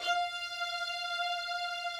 Updated string samples
strings_065.wav